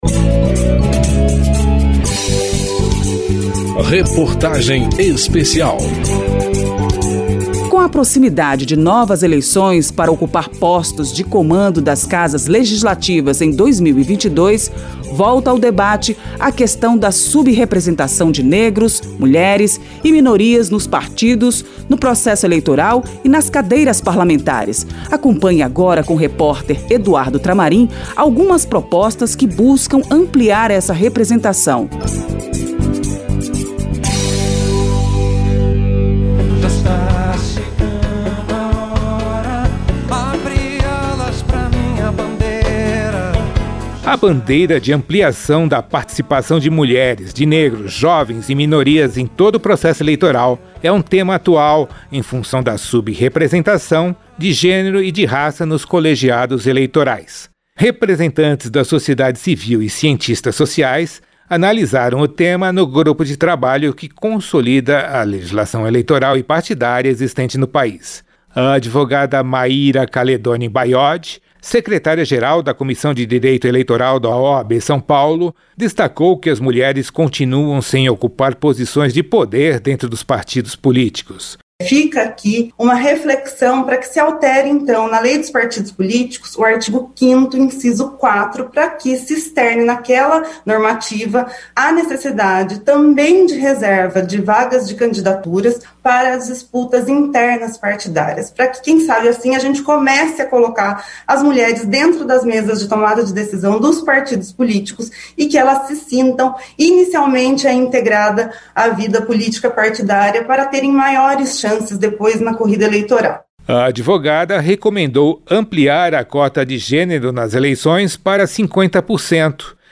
Reportagem Especial
A bandeira de ampliação da participação de mulheres, negros, jovens e minorias em todo o processo eleitoral é um tema atual em função da sub-representação de gênero e raça nos colegiados eleitorais. Nesta terceira reportagem, parlamentares, representantes da sociedade civil e cientistas sociais analisam o tema sob a luz da legislação eleitoral e partidária existente no país.